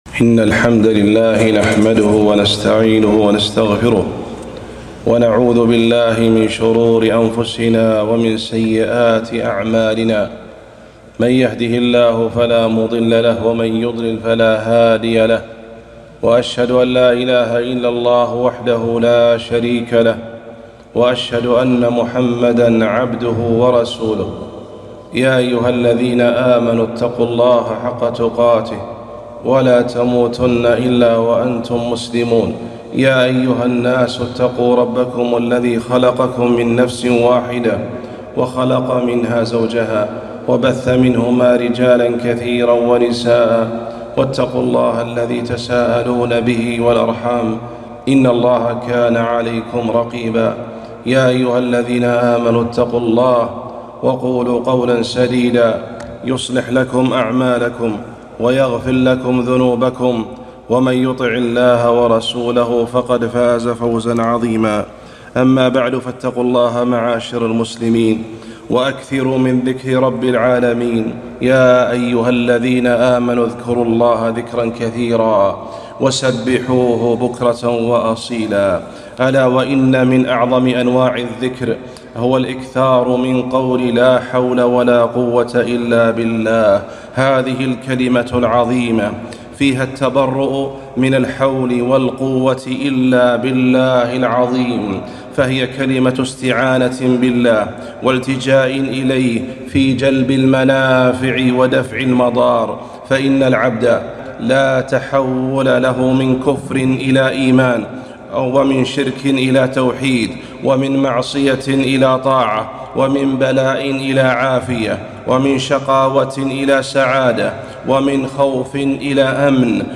خطبة - فضائل الحوقلة